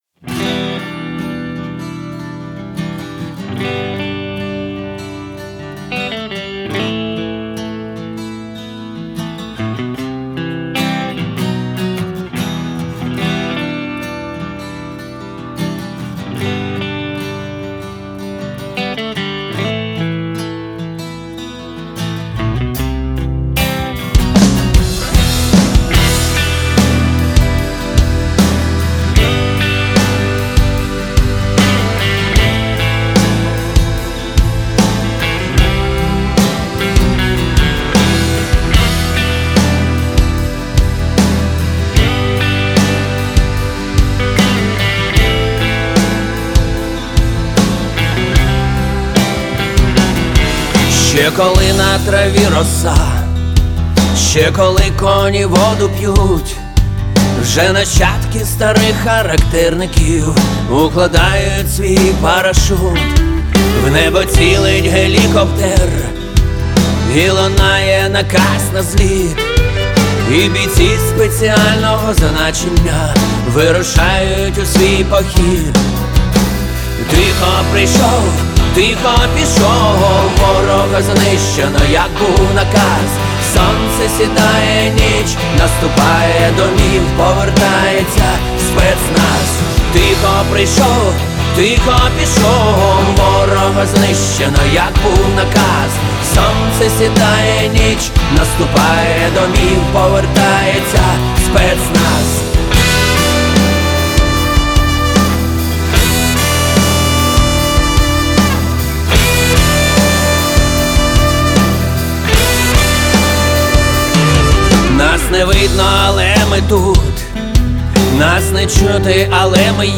Украинский рок